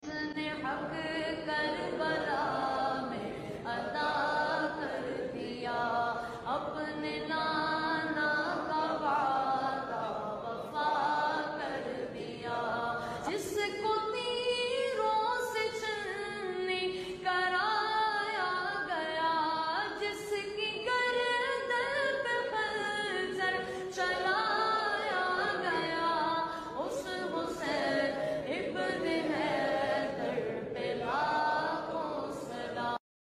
NAAT STATUS